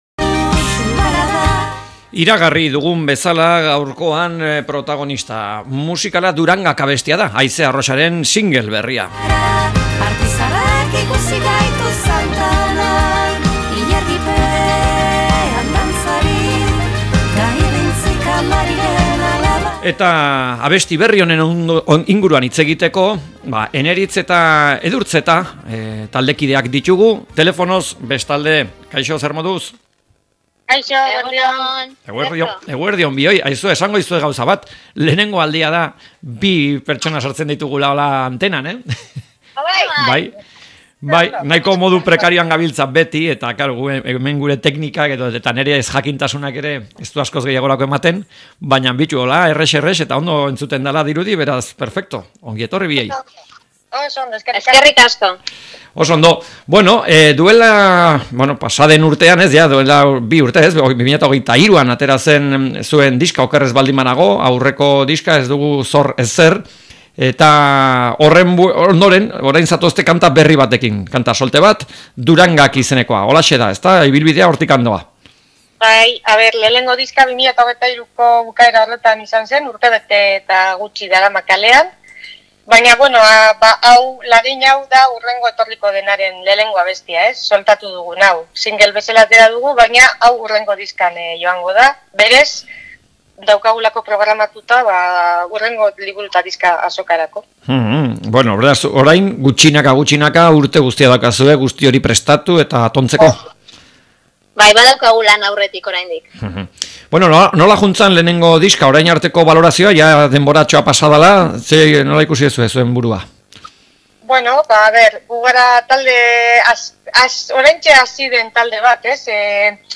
Elkarrizketak